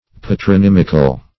Patronymical \Pa`tro*nym"ic*al\, a.